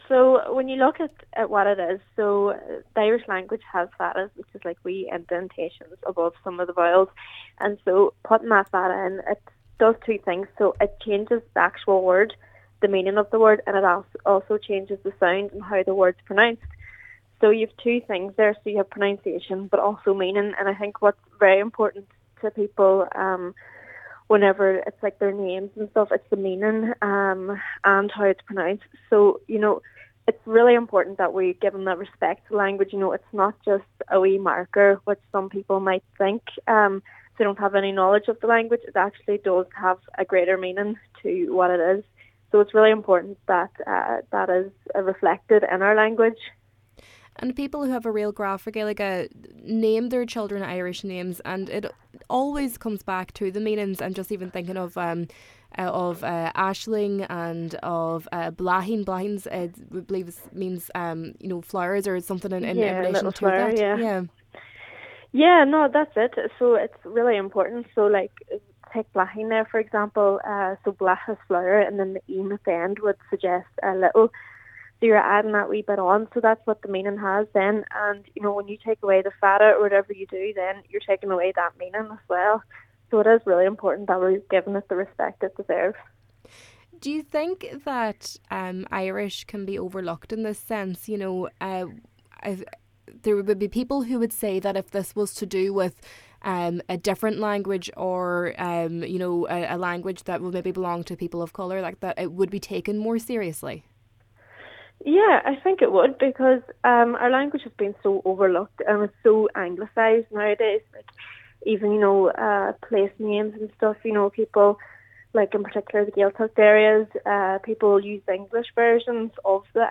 East Donegal Cllr Dakota Ní Mheanmann says the inclusion of fadas in personal records boils down to respect: